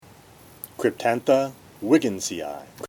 Pronunciation/Pronunciación:
Cryp-tán-tha wíg-gins-i-i